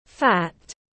Thịt mỡ tiếng anh gọi là fat, phiên âm tiếng anh đọc là /fæt/